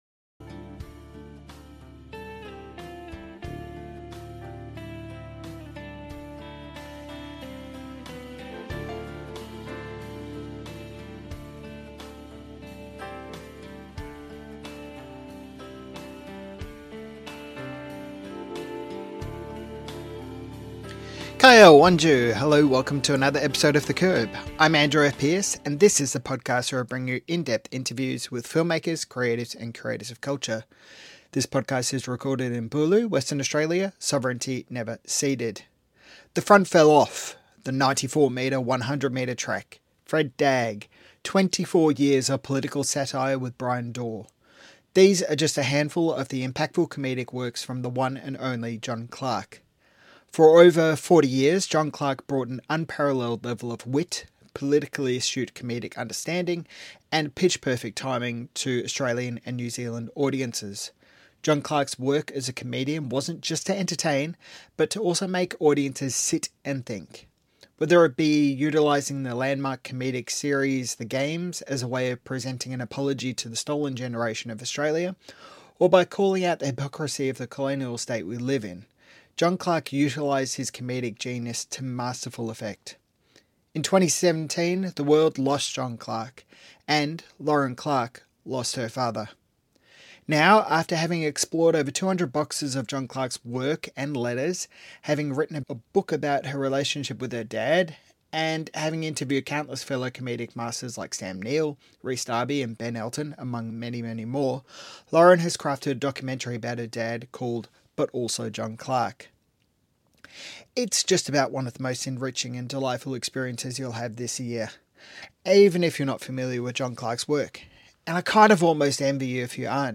Subscribe to The Curb podcast via RSS feed, Apple Podcasts, Spotify, or iHeart Radio.